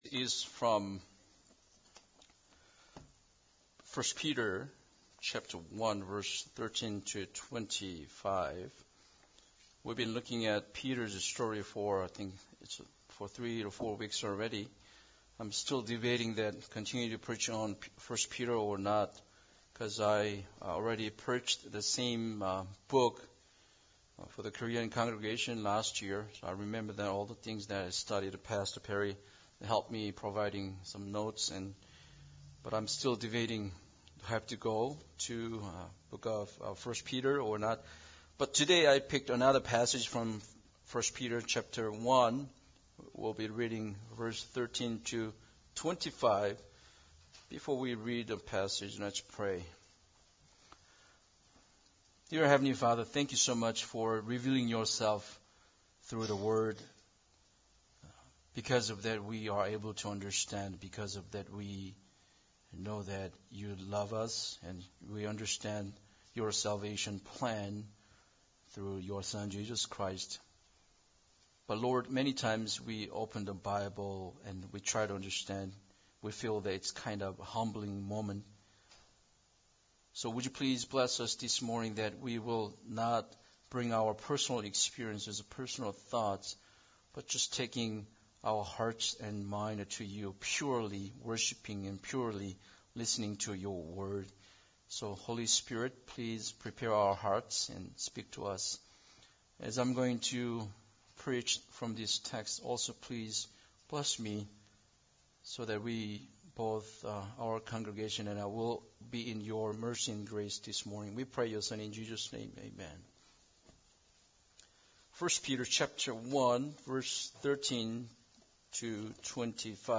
1 Peter 1:13-25 Service Type: Sunday Service Bible Text